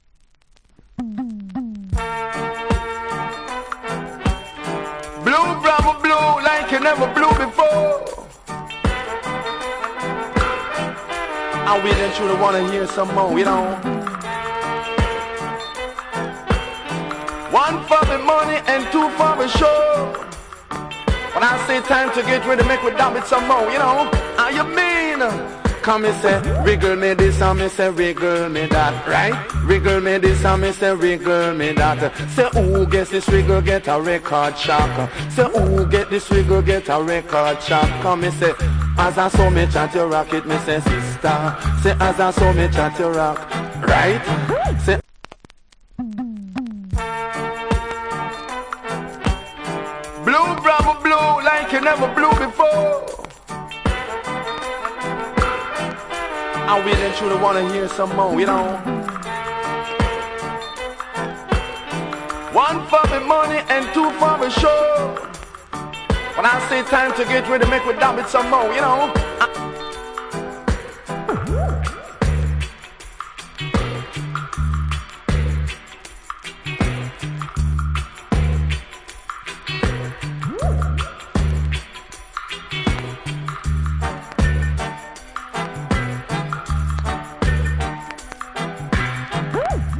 TOP >SOLD OUT >VINTAGE 7inch > REGGAE
Wicked DJ.